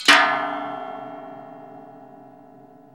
METAL HIT 3.wav